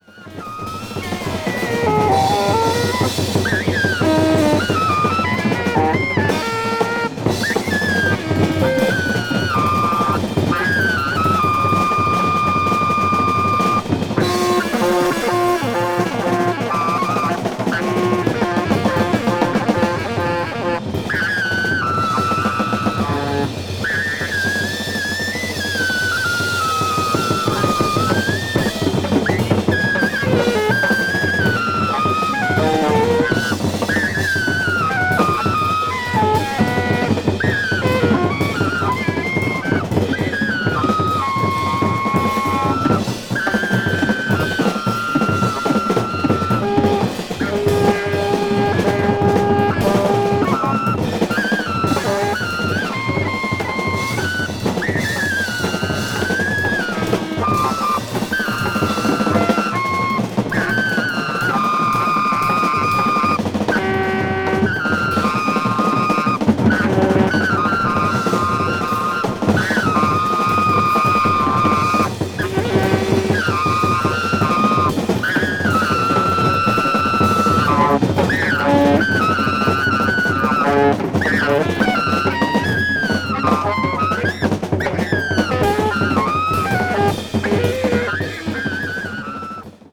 media : EX/EX(some slightly noises.)
avant-jazz   free improvisation   free jazz   free music